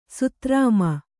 ♪ sutrāma